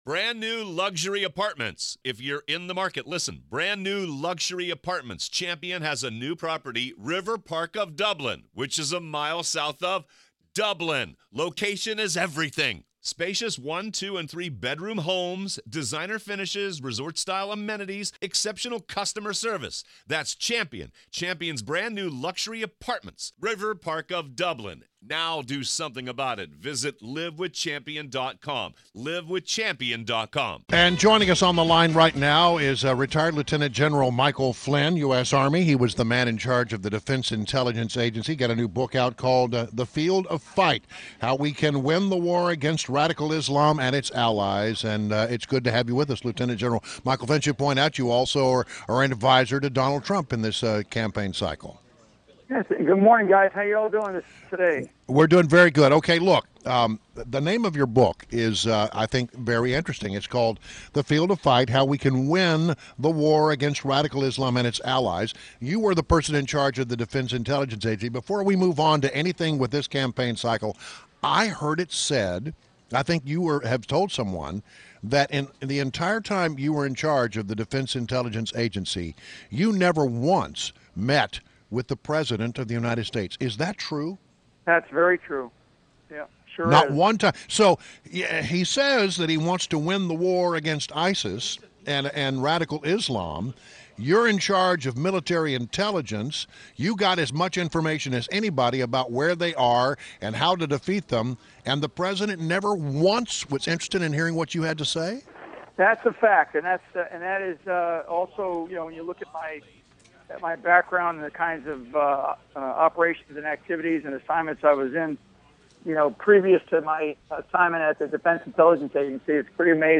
WMA L Interview - Michael Flynn - 07.27.16